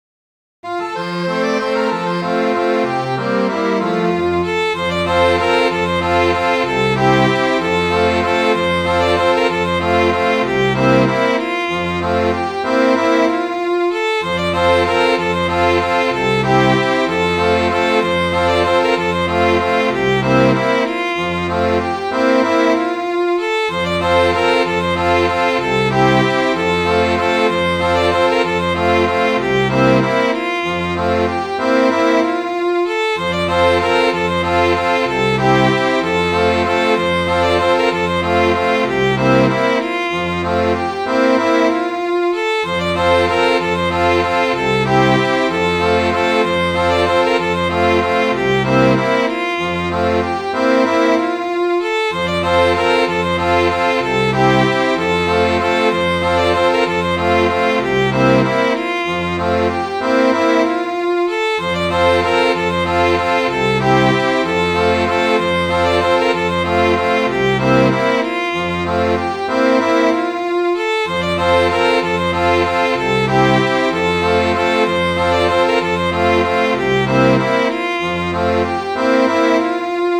Midi File, Lyrics and Information to The Saucy Sailor Boy
saucysailor.mid.ogg